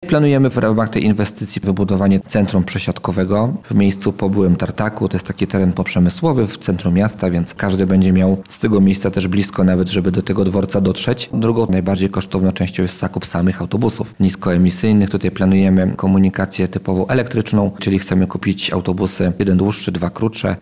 Chcemy to zmienić – mówi Radiu Lublin burmistrz Włodawy Wiesław Muszyński: – Planujemy w ramach tej inwestycji wybudowanie centrum przesiadkowego w miejscu po byłym tartaku.